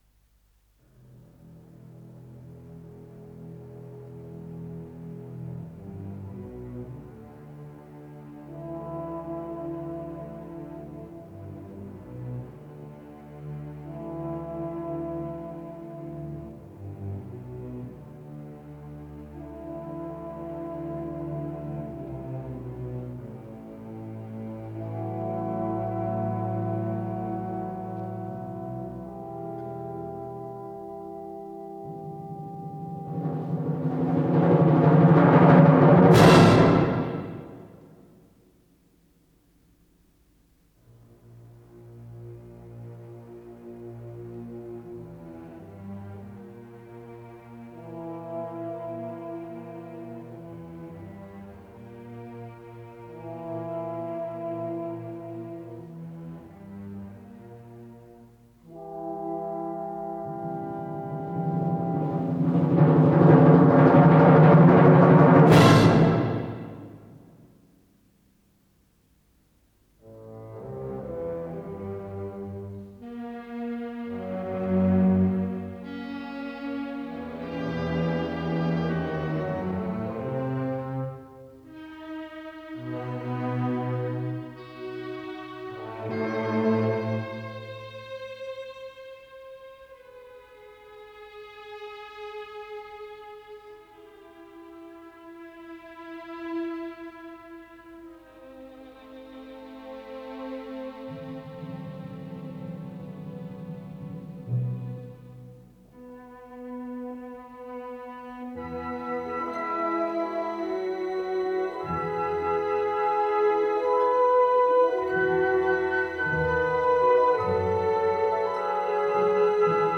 Исполнитель: Московский Государственный симфонический оркестр
Название передачи Гроза Подзаголовок Увертюра к драме А.Н. Островского, соч. 1864 г. Код ПКС-025237 Фонд Без фонда (ГДРЗ) Редакция Музыкальная Общее звучание 00:14:50 Дата добавления 13.10.2024 Прослушать